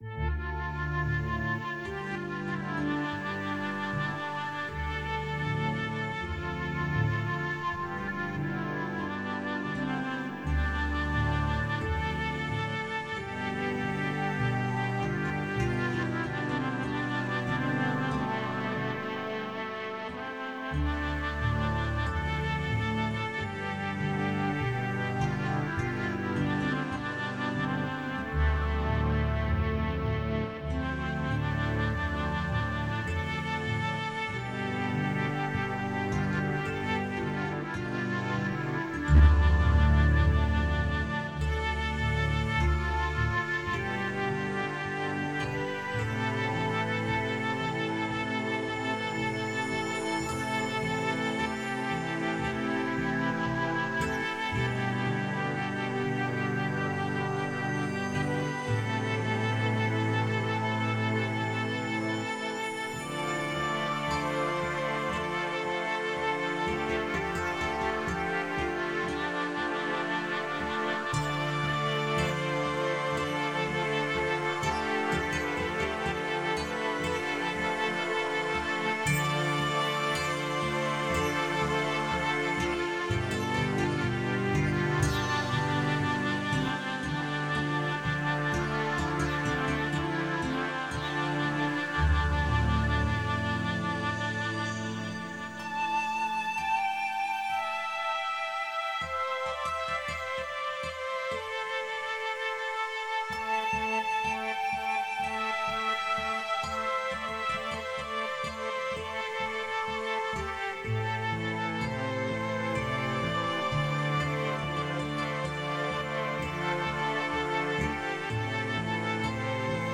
Мистическая музыка Духовная музыка Медитативная музыка